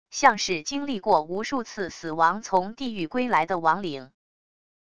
像是经历过无数次死亡从地狱归来的亡灵wav音频